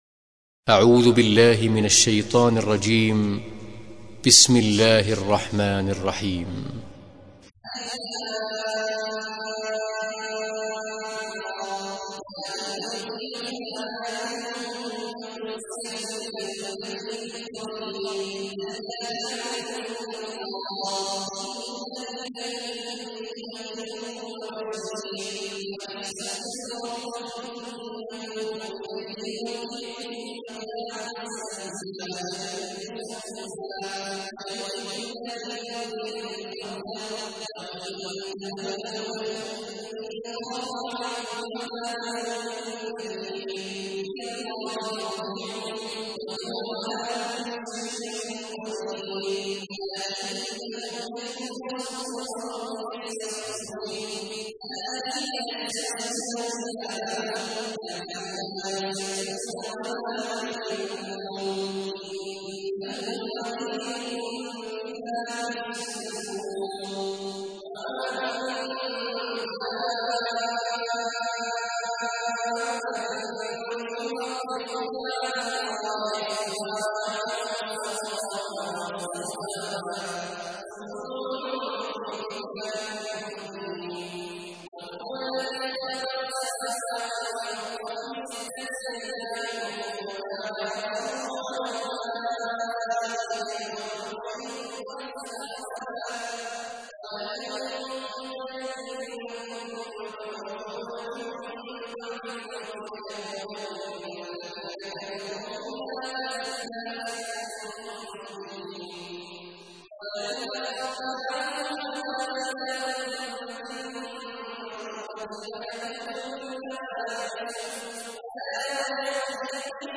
تحميل : 11. سورة هود / القارئ عبد الله عواد الجهني / القرآن الكريم / موقع يا حسين